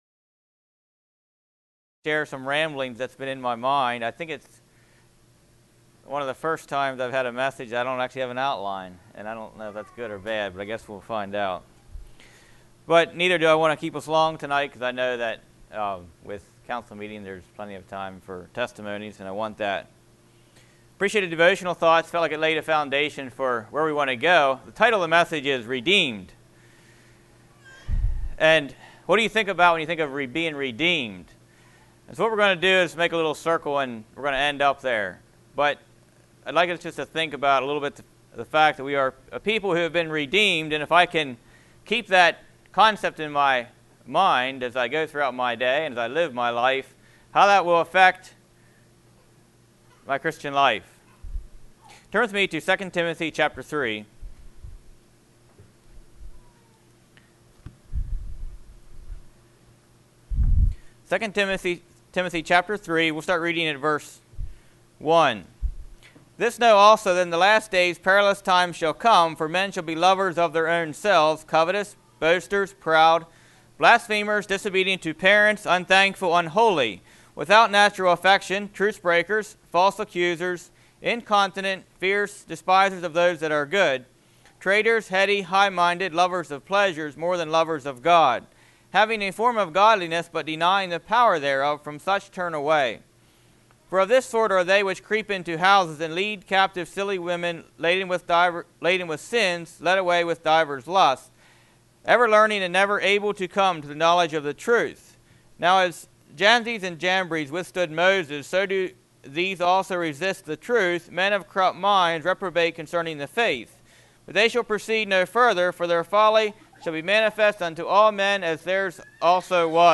Redeemed- This is a message that was preached at our Counsel Meeting Categories